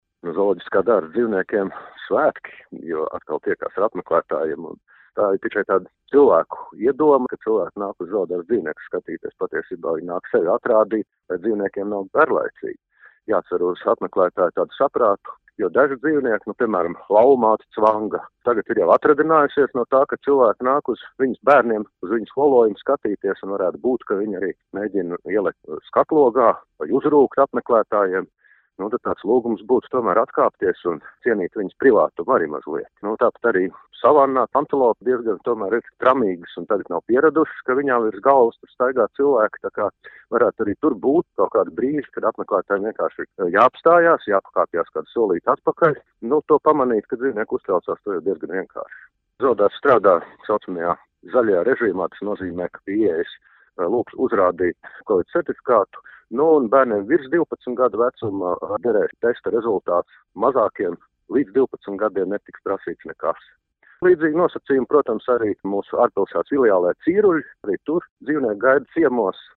Radio Skonto Ziņās par Rīgas Zooloģiskā dārza atvēršanu apmeklētājiem